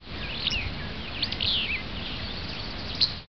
Willow flycatcher
Empidonax traillii call
This bird's song is a sneezed fitz-bew. The call is a dry whit.